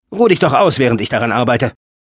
File File history File usage Wheatley_sp_a2_bts5_hack02_de.wav  (file size: 20 KB, MIME type: audio/mp3 ) Summary Wheatley German voice files Licensing This is an audio clip from the game Portal 2 .